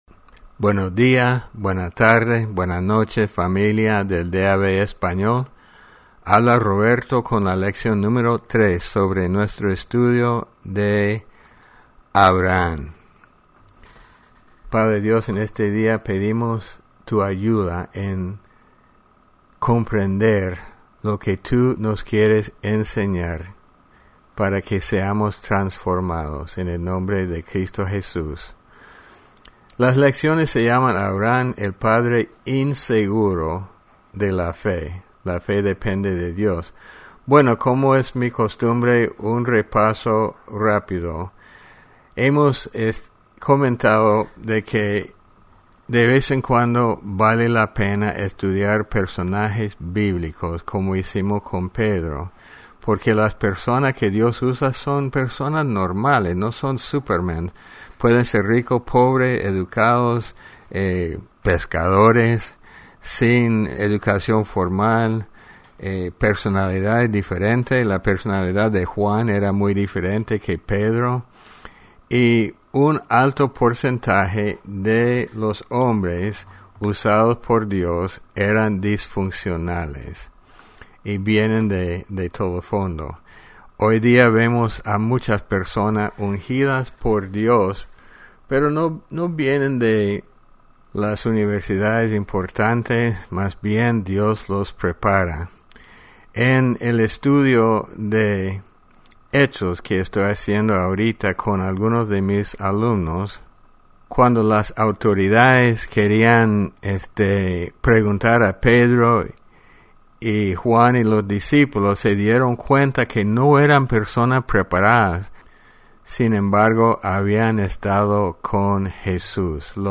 Lección #3 Abraham – El Padre inseguro de la Fe